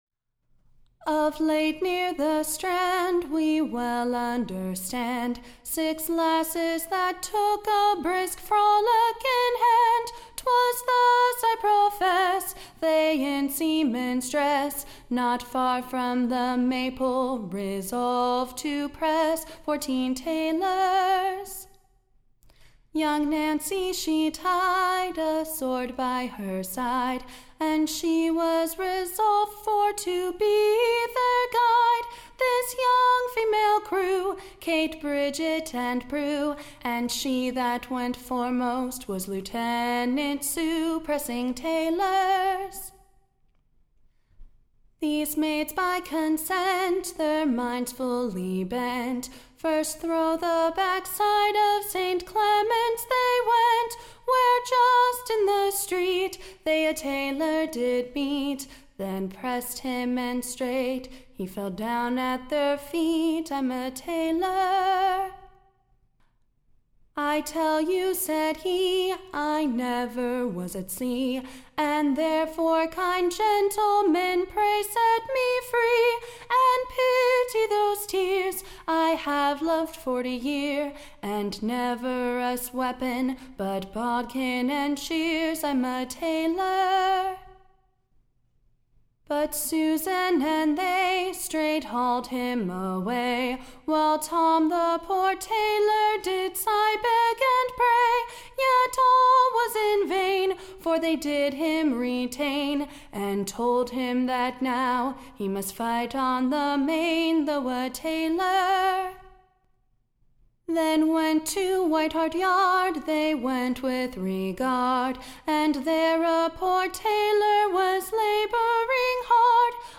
Recording Information Ballad Title The Maidens Frollick: / OR, / A brief Relation how Six Lusty Lasses has Prest full Fourteen Taylors on / the backside of St. Clements, and the other adjacent Places.